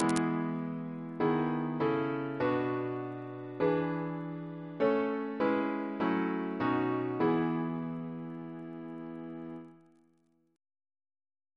Single chant in E Composer: Ivor Keys (1919-1995) Reference psalters: ACP: 15